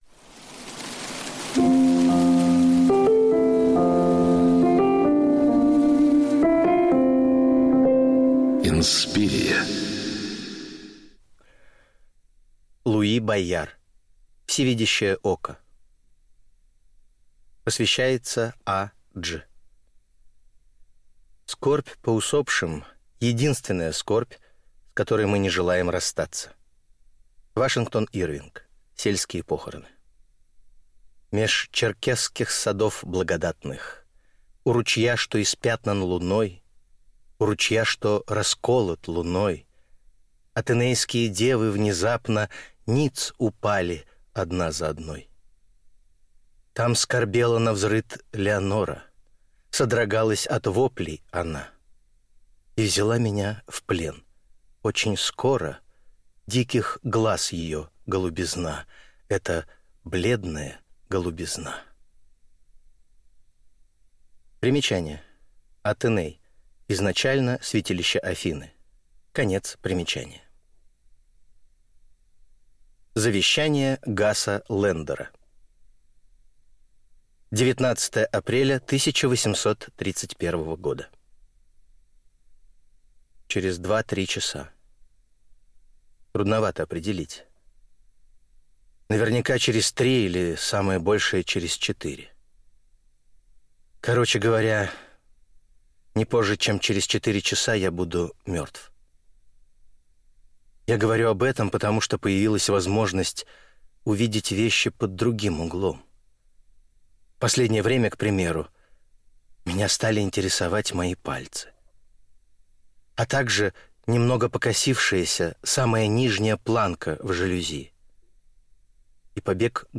Аудиокнига Всевидящее око | Библиотека аудиокниг